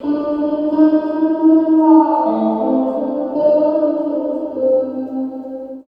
134 GTR 6 -L.wav